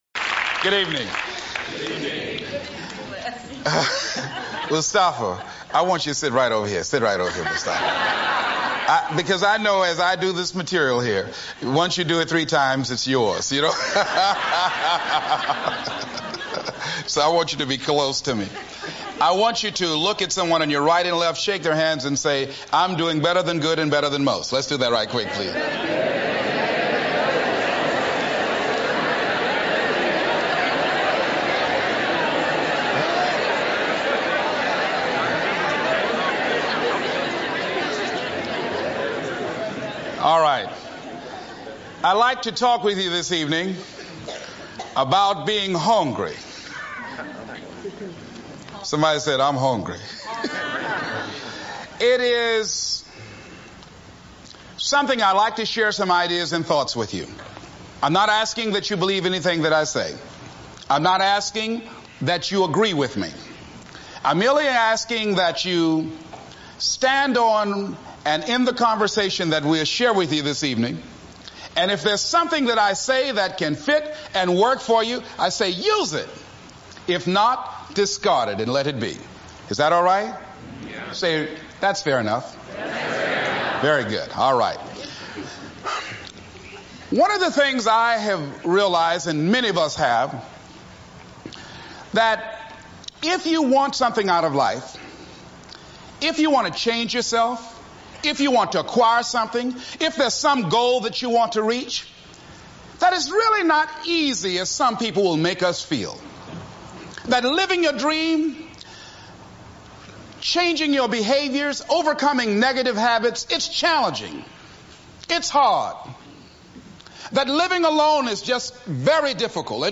Following is the full transcript of Les Brown’s You Gotta be Hungry speech.
Les Brown – Motivational speaker, author
Les-Brown-You-Gotta-be-Hungry-Les-Brown-Greatest-Speech.mp3